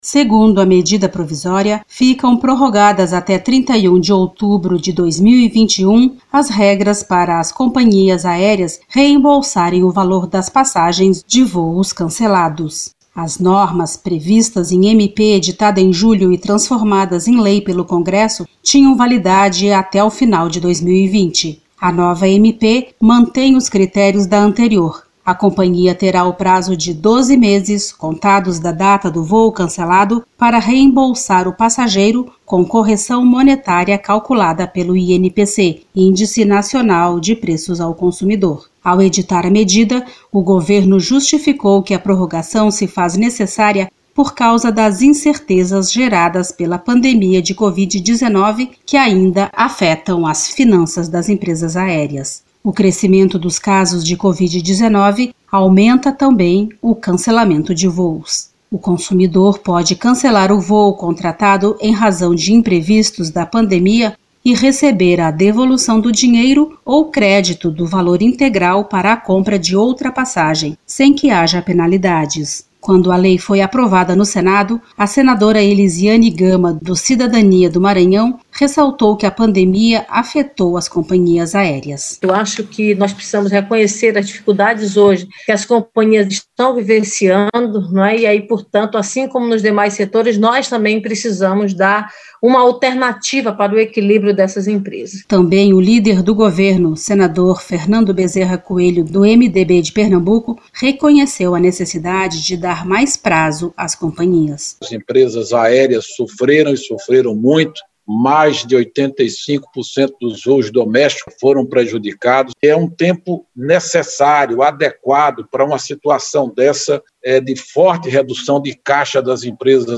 O senador Fernando Bezerra Coelho (MDB-PE), líder do governo, e senadora Eliziane Gama (Cidadania-MA) ressaltaram que a pandemia trouxe dificuldades para as empresas aéreas.
Reportagem